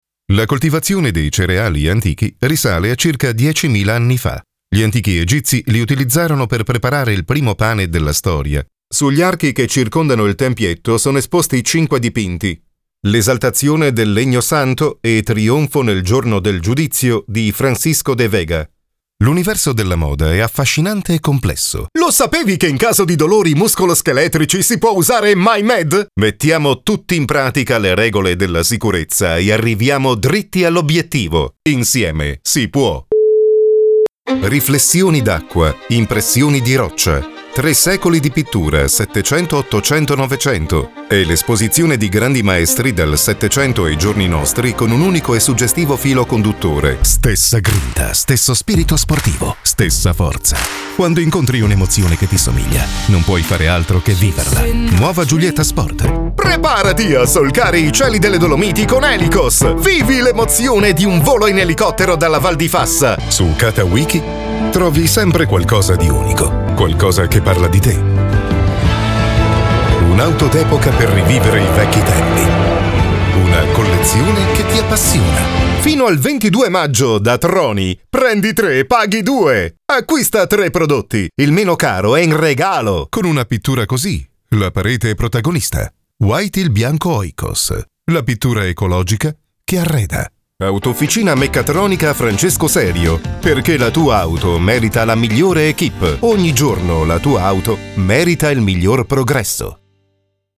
Commerciale, Enjouée, Mature, Chaude, Corporative
Corporate